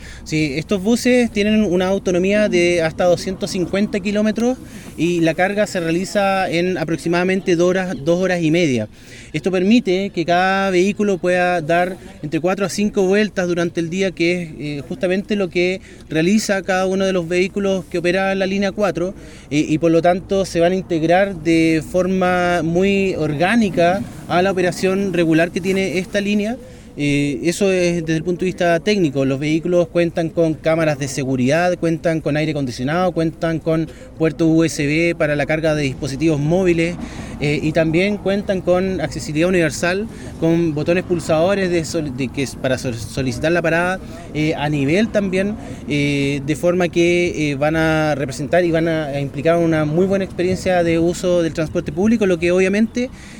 En tanto, el seremi de Transportes, Pablo Joost, enumeró los atributos que tiene cada bus eléctrico al servicio de la comunidad de Puerto Montt.